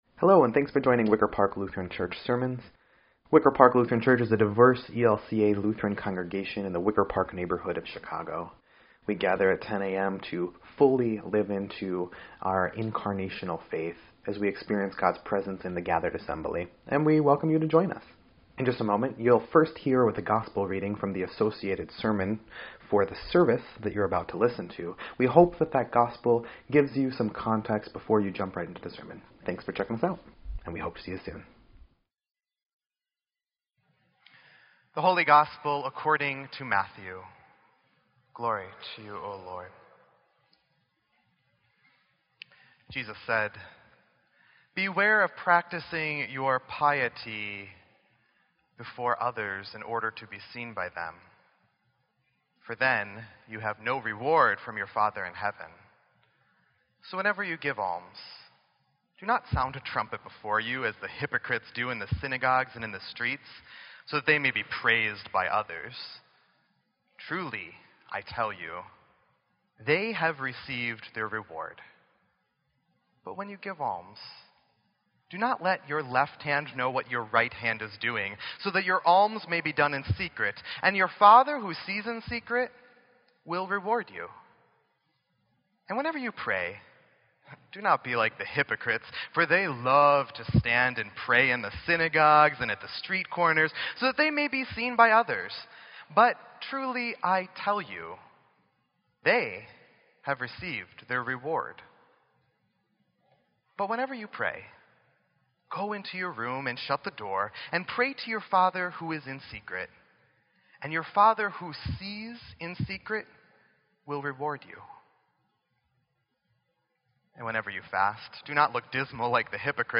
Ash Wednesday